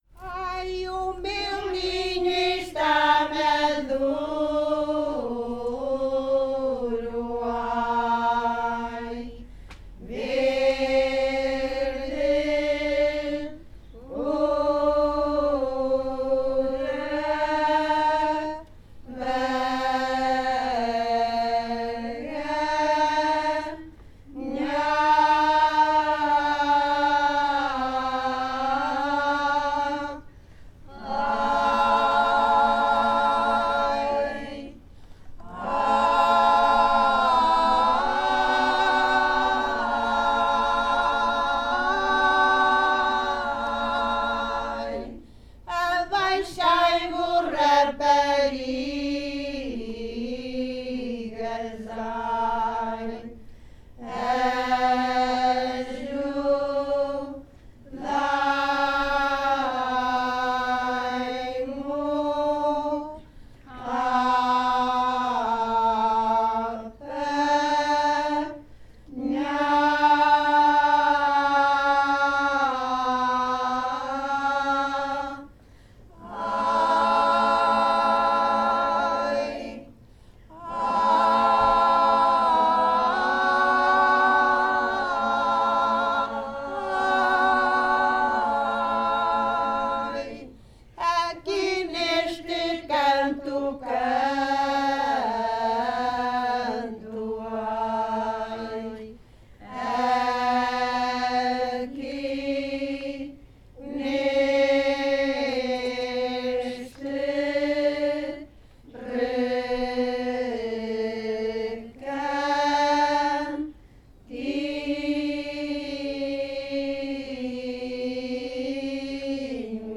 Grupo Etnográfico de Trajes e Cantares do Linho de Várzea de Calde durante o encontro SoCCos em Portugal - Arranque do linho.